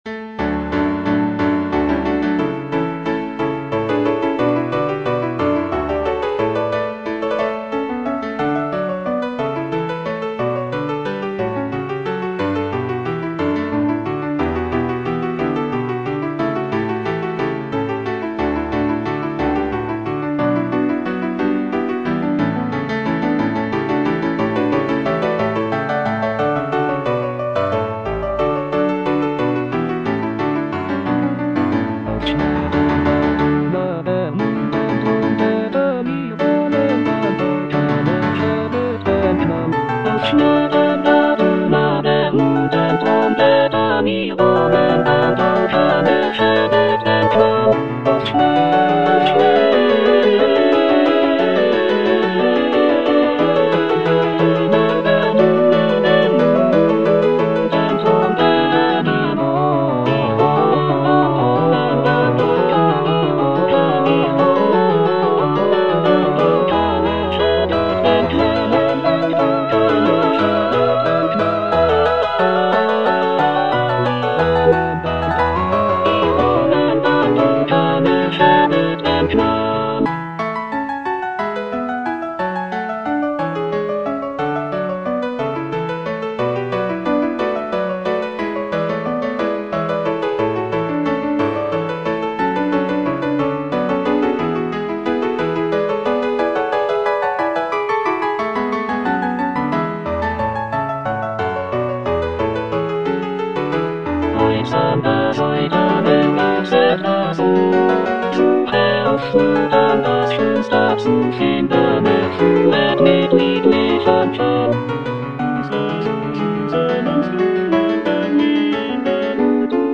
Choralplayer playing Cantata
It is a festive and celebratory work featuring lively trumpet fanfares and joyful choruses.